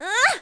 Juno-Vox_Attack2.wav